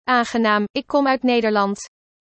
Premium International Voices
Female Dutch